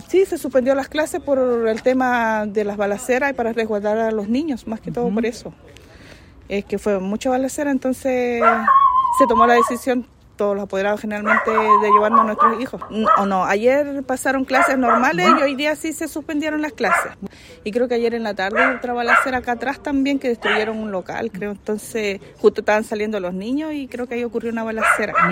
Por todos estos hechos de violencia, una apoderada reportó la suspensión de clases en la Escuela Básica Horacio Johnson Gana.